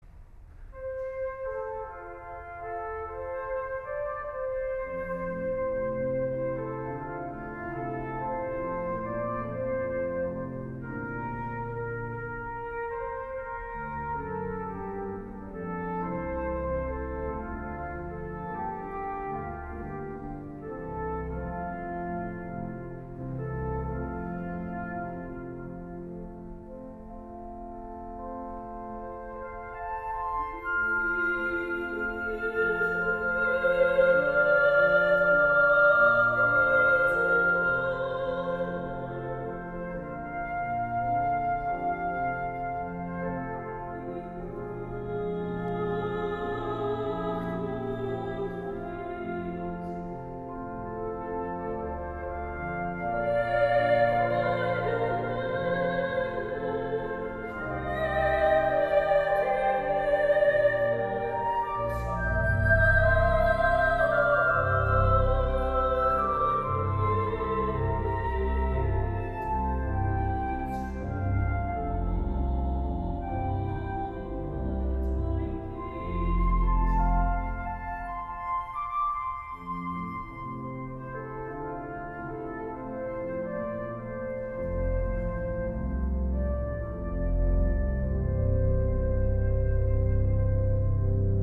Orgel
Flöte
Sopranistin